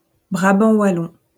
Brabant Wallonia (bahasa Prancis: Brabant wallon [bʁabɑ̃ walɔ̃] (